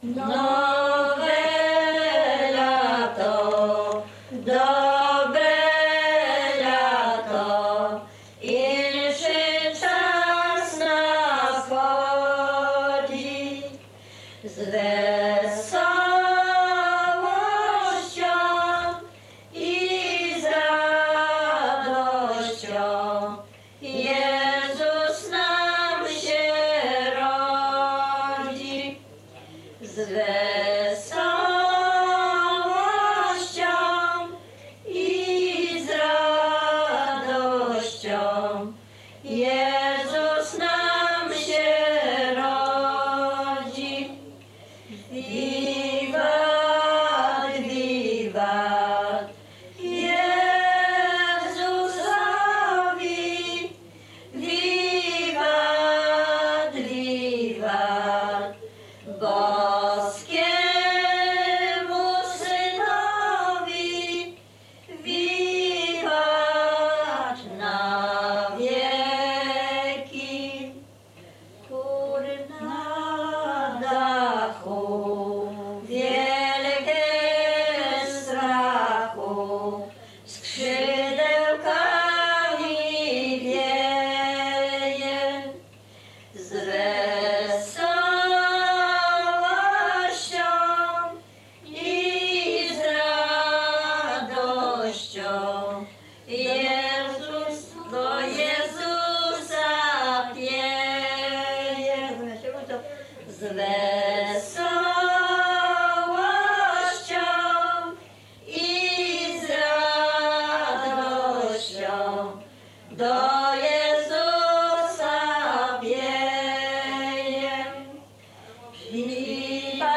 Dolny Śląsk, powiat bolesławiecki, gmina Osiecznica, wieś Przejęsław
Kolęda